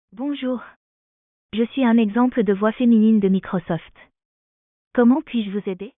Example of the voices available in our catalog
voix-microsoft2.wav